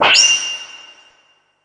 SFX魔法刀剑音效下载